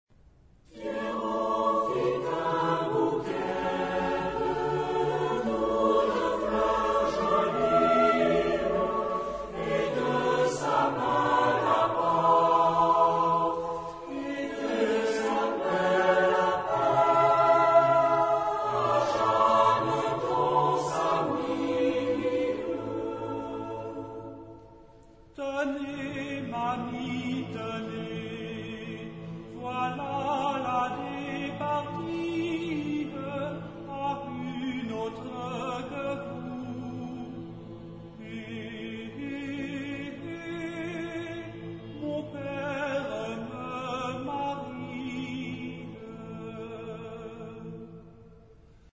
Genre-Style-Forme : Profane ; Populaire
Type de choeur : SATB  (4 voix mixtes )
Tonalité : sol majeur
Origine : Bretagne ; Ile de France